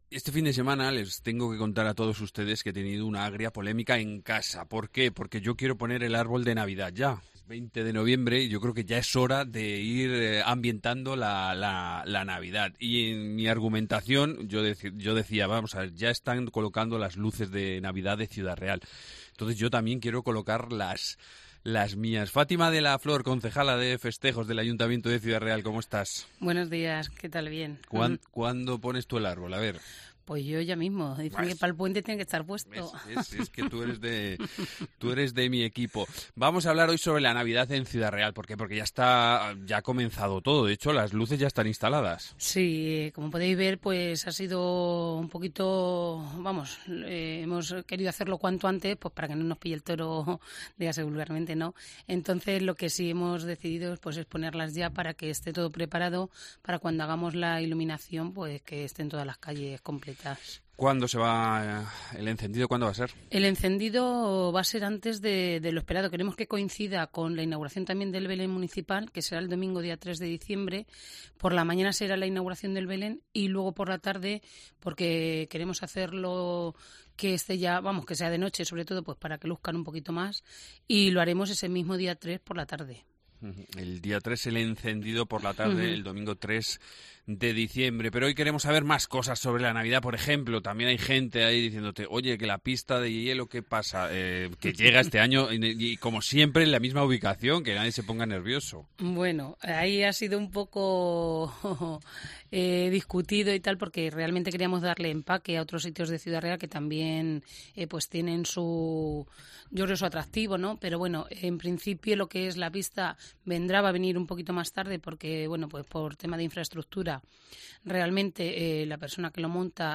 Entrevista con Fátima de la Flor, concejala de Festejos